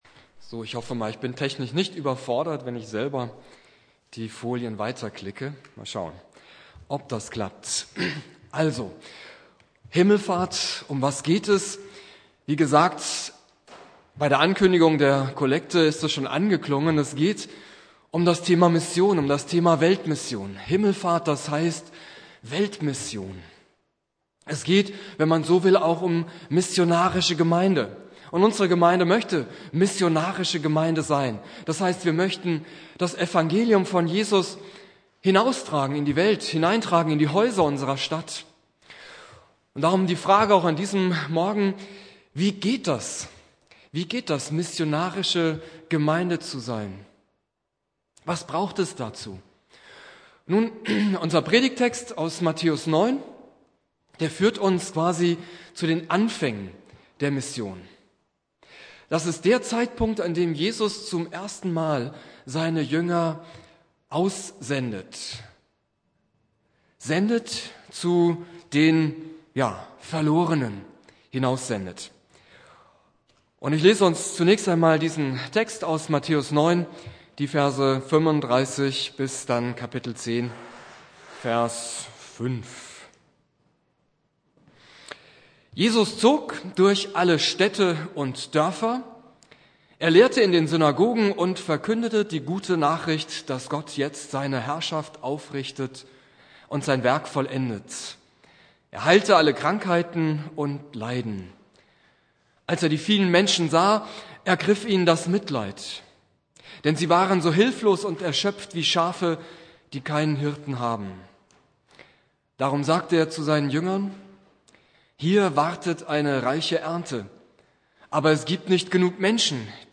Predigt
Christi Himmelfahrt Prediger